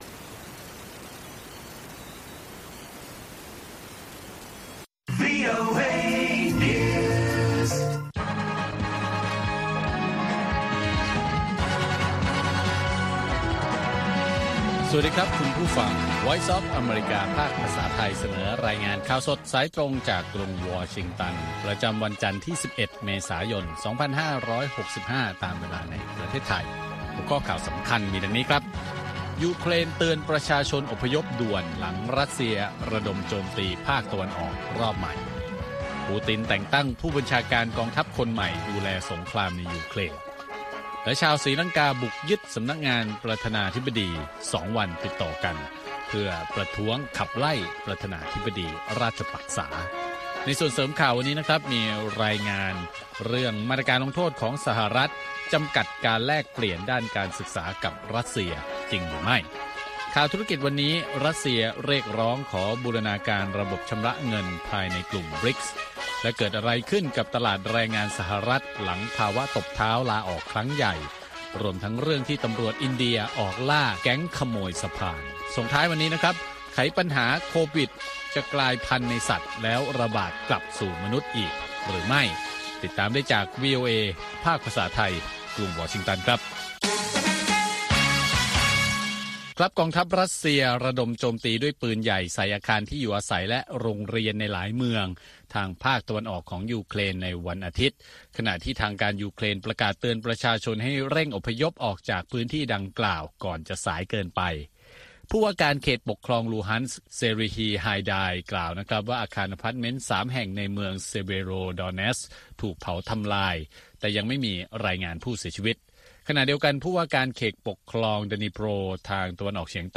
ข่าวสดสายตรงจากวีโอเอ ภาคภาษาไทย วันจันทร์ ที่ 11 เมษายน 2565